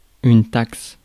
Ääntäminen
Synonyymit impôt racket Ääntäminen France: IPA: [taks] Haettu sana löytyi näillä lähdekielillä: ranska Käännös Ääninäyte Substantiivit 1. tax US 2. toll 3. levy 4. duty US Suku: f .